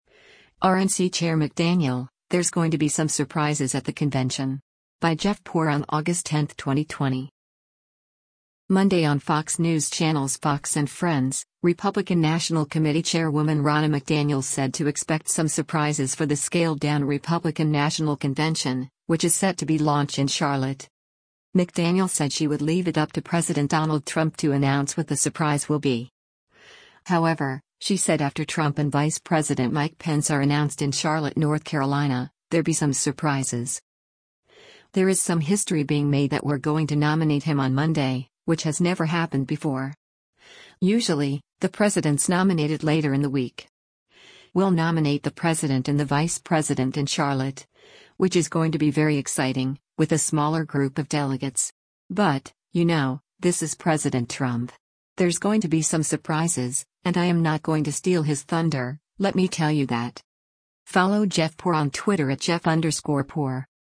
Monday on Fox News Channel’s “Fox & Friends,” Republican National Committee chairwoman Ronna McDaniel said to expect some surprises for the scaled-down Republican National Convention, which is set to be launch in Charlotte.